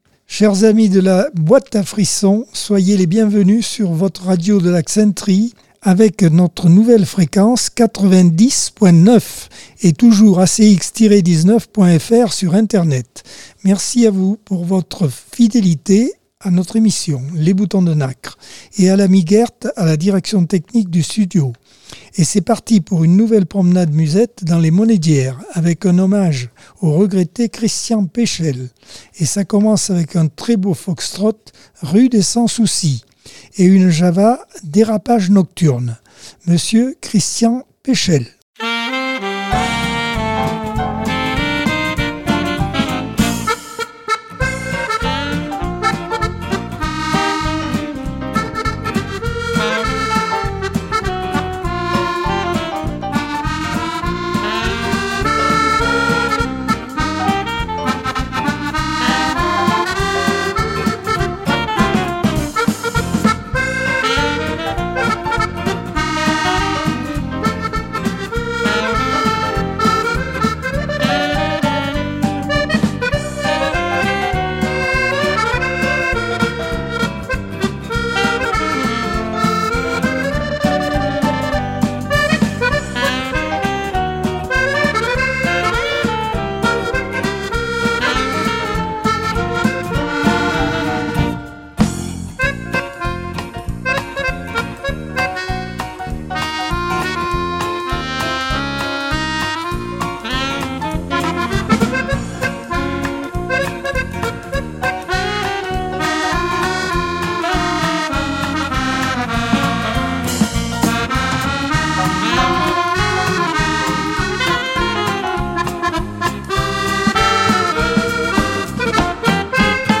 Accordeon 2024 sem 27 bloc 1 - Radio ACX